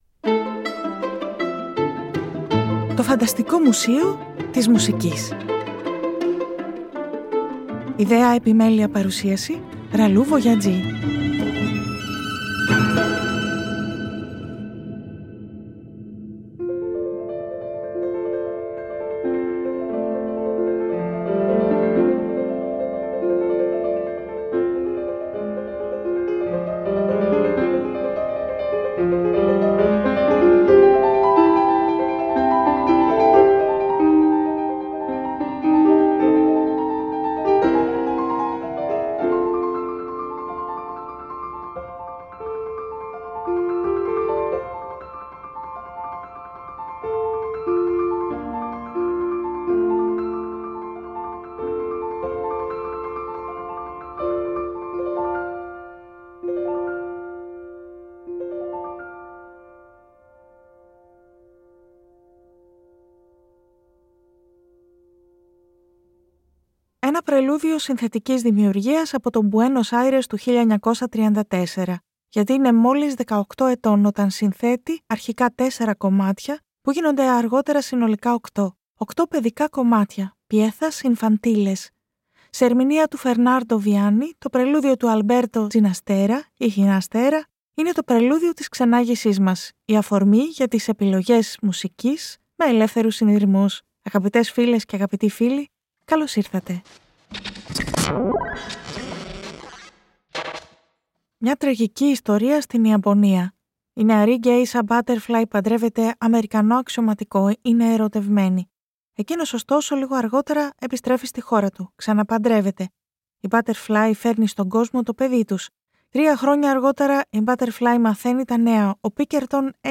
Με αφορμή ένα Παιδικό Κομμάτι για Πιάνο του Alberto Ginastera ξενάγηση με επιλογές ελεύθερων συνειρμών σε μουσικές μορφές, ιστορικές εποχές, τόπους της μουσικής: παιδικό κομμάτι για πιάνο, μουρμουρητό από μια όπερα, πολυφωνικό τραγούδι για το μάθημα σύνθεσης, σπουδή που δεν έχει γραφτεί για μελέτη, σκέρτσο για ορχήστρα που μάλλον ήταν ένα τρίο, τραγούδι αγάπης, μουσικό δώρο…
Το 2024 Το ΦΑΝΤΑΣΤΙΚΟ ΜΟΥΣΕΙΟ της ΜΟΥΣΙΚΗΣ (Τhe FANTASTIC MUSEUM of MUSIC) είναι μια ραδιοφωνική εκπομπή , ένας «τόπος» φαντασίας στην πραγματικότητα .